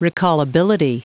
Prononciation du mot recallability en anglais (fichier audio)
Prononciation du mot : recallability